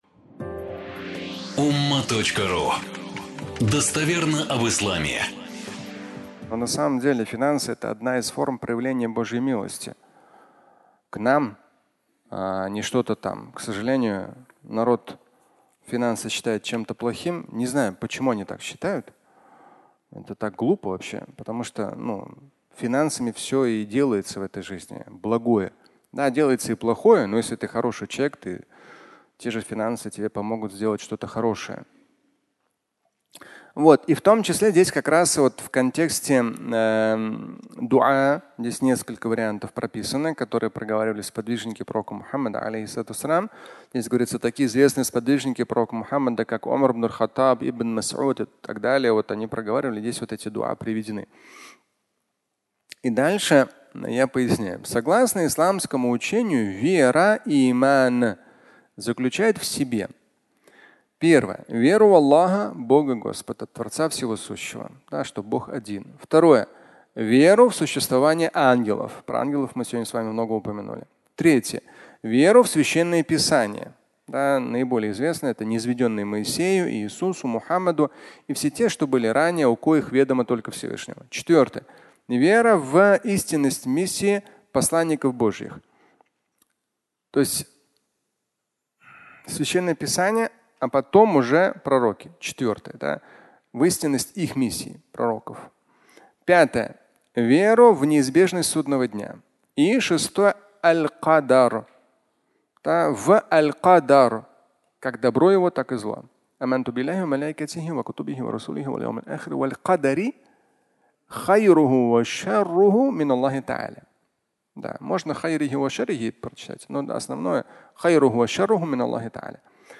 Столпы веры (аудиолекция)